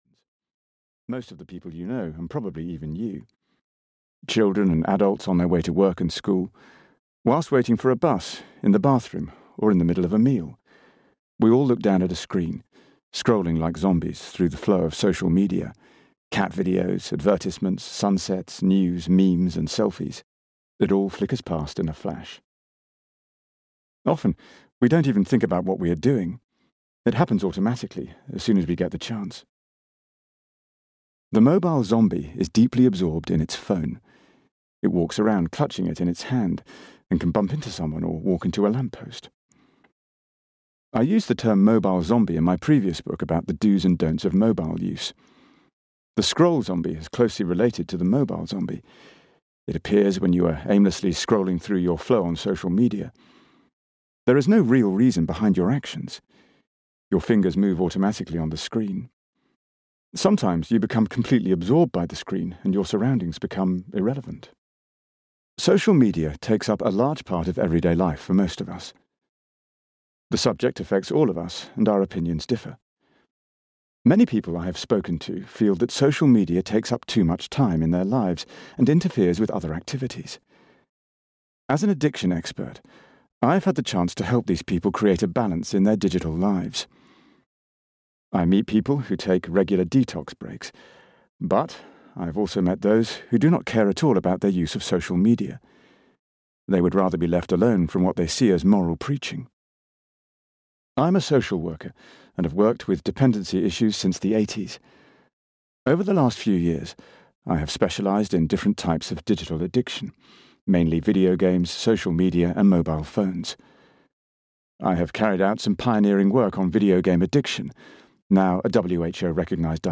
Audio knihaScroll Zombies: How Social Media Addiction Controls our Lives (EN)
Ukázka z knihy